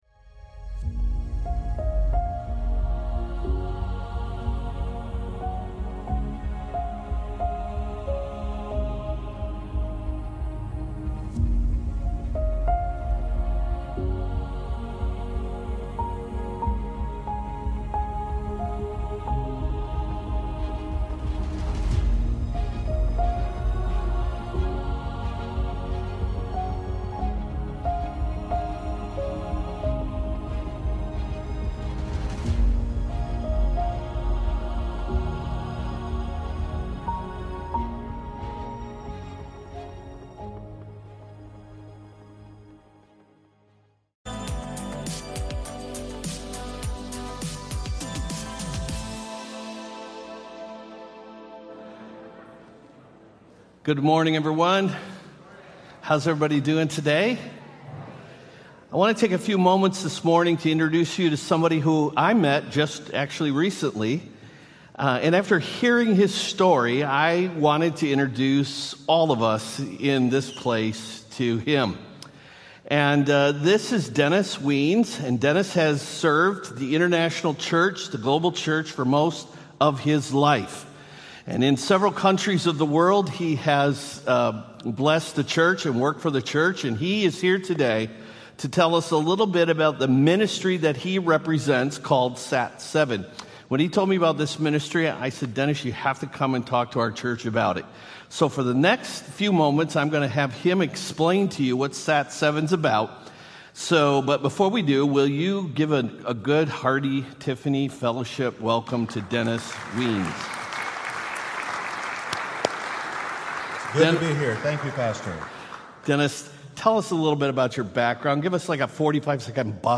Missionary reports from certain regions of the world